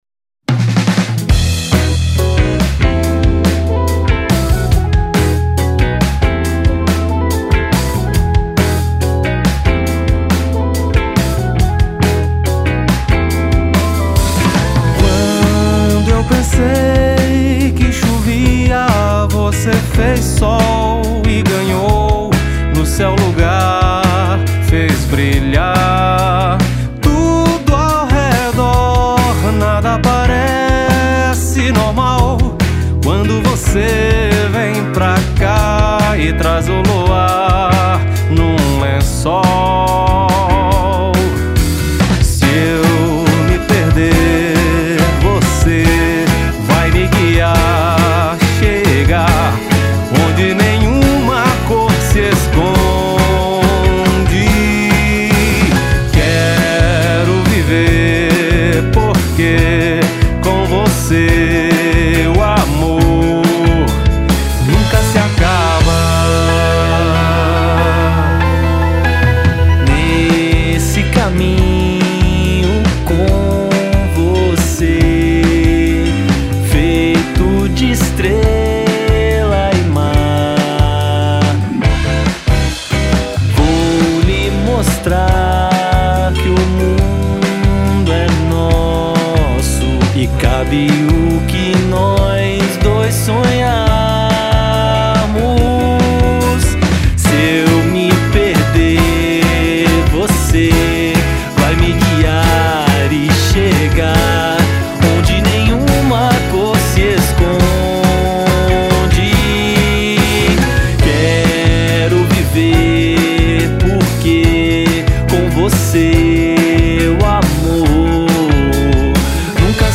1278   03:30:00   Faixa:     Rock Nacional